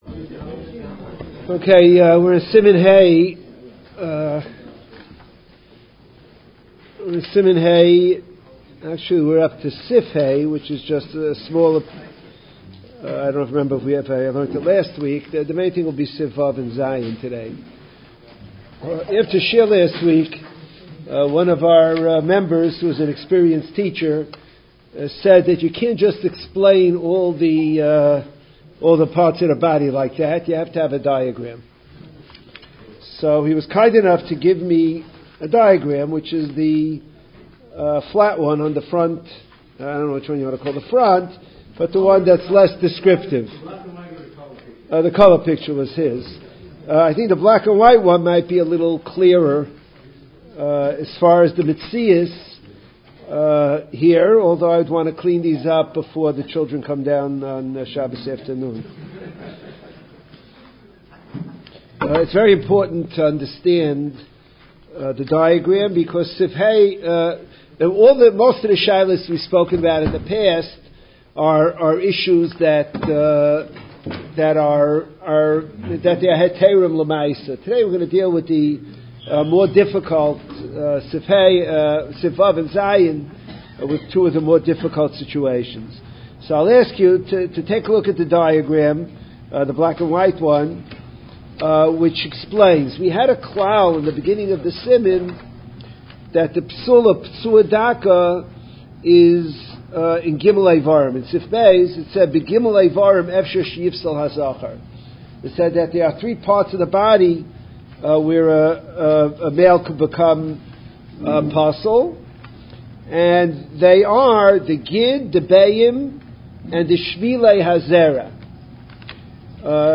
Shiurim and speeches on Gemarah, Halachah, Hashkofo and other topics, in mp3 format